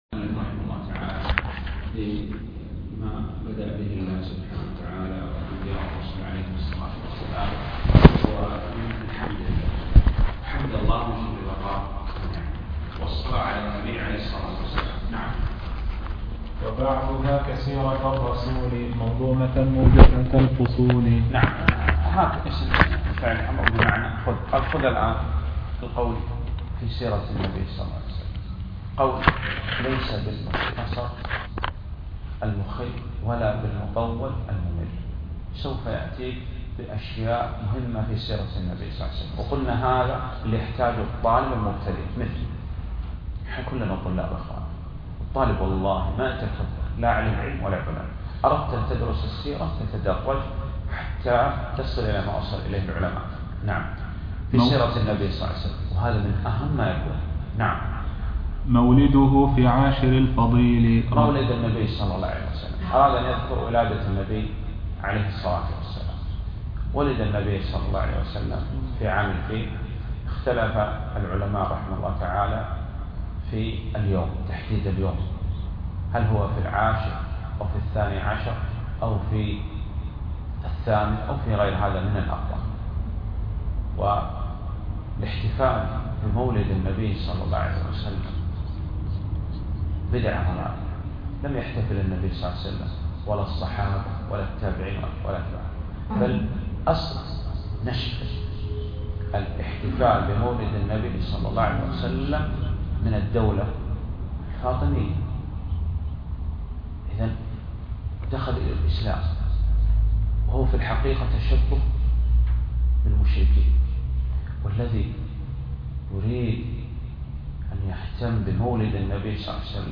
التسجيل من شرح الشيخ لأول بيت في الأرجوزة وللأسف لم يتم تسجيل المقدمة
الدرس الأول